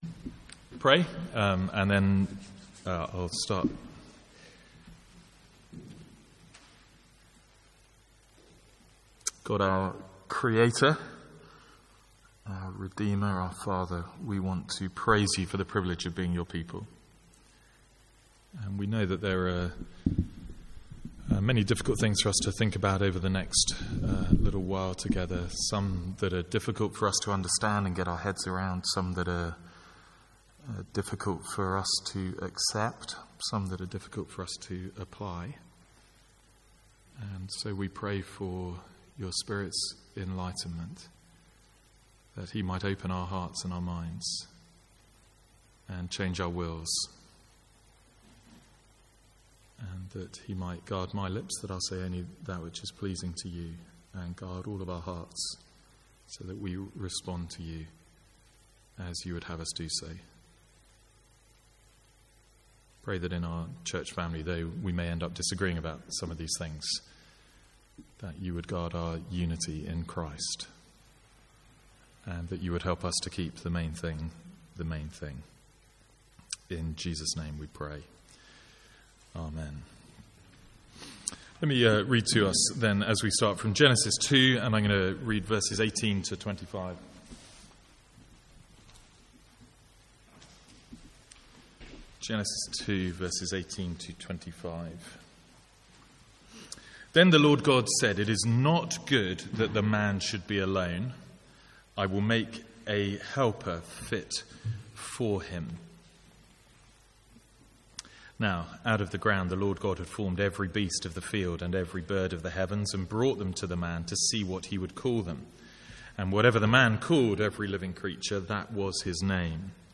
Seminar from MYC16: Identity.